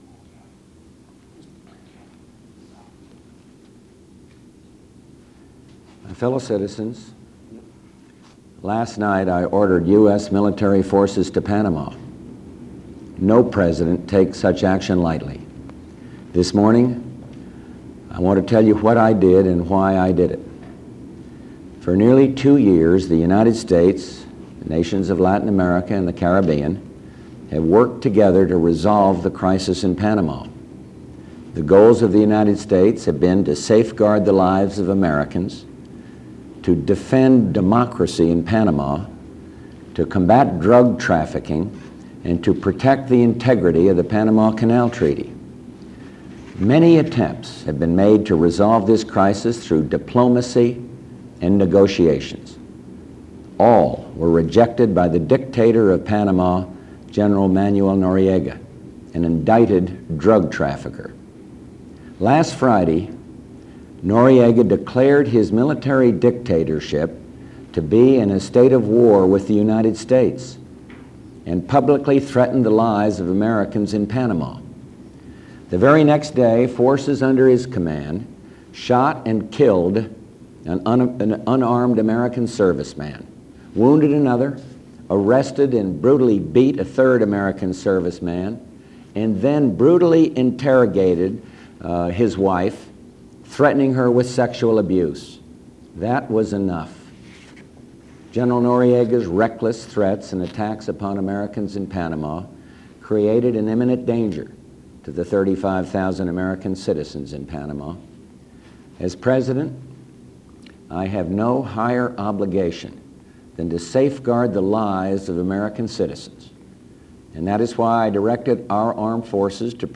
History  Speeches
Address to Nation on Panama Invasion